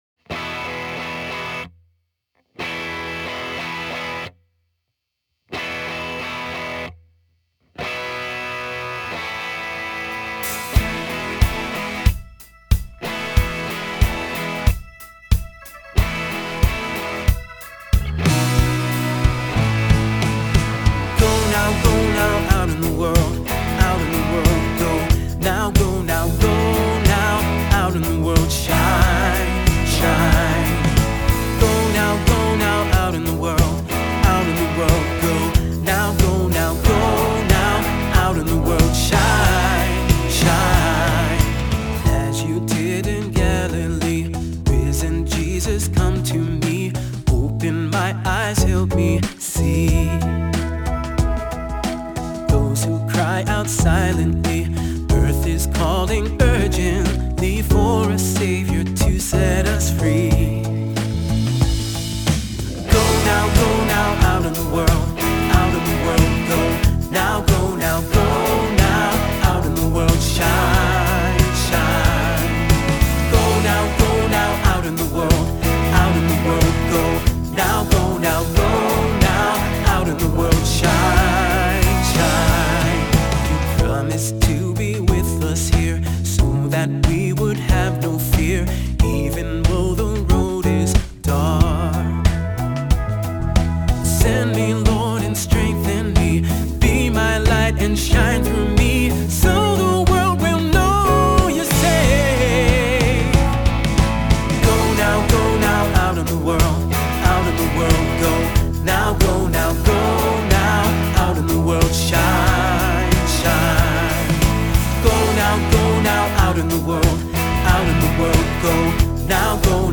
Voicing: Assembly,SATB, cantor